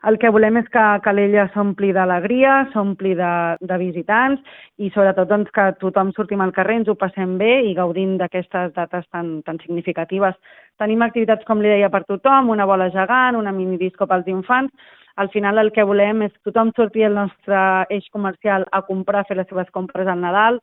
Cindy Rando, la tinenta d’Alcaldia de Promoció Econòmica, Comerç i Consum explica que l’objectiu és crear un ambient festiu que ompli els carrers de vida i activitat, i que afavoreixi també el comerç local.